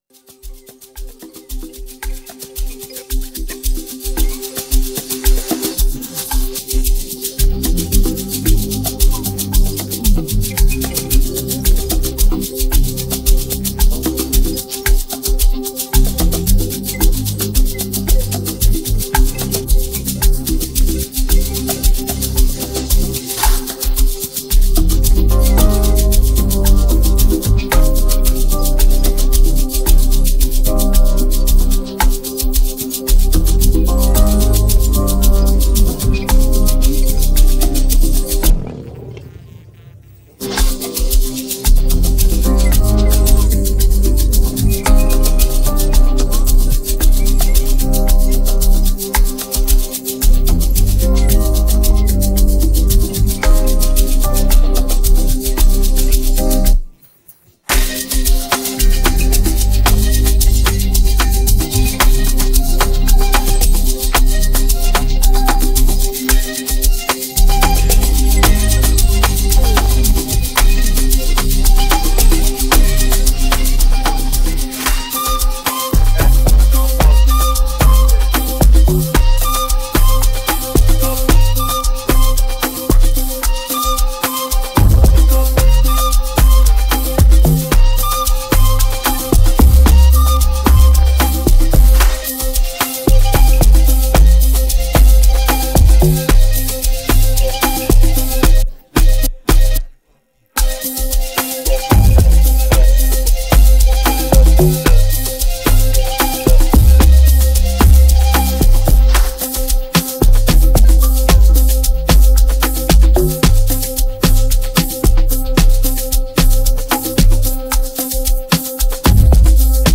here comes another fire Amapiano Instrumental